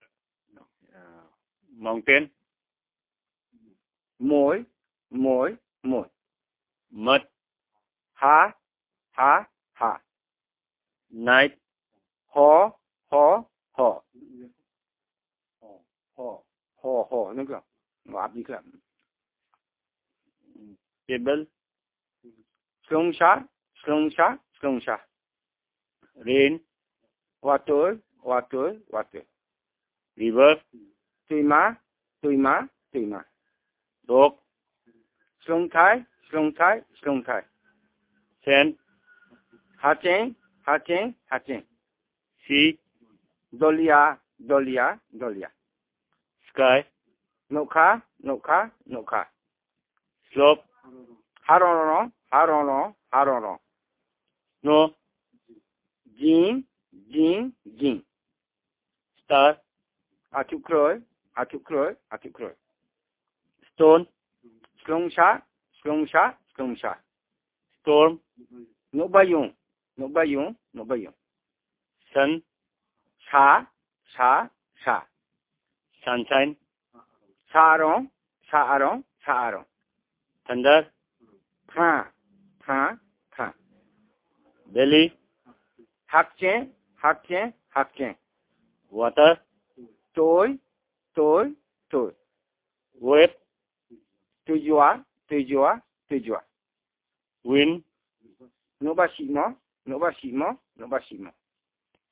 dc.coverage.spatialDepachrera, Gumoti
dc.description.elicitationmethodInterview method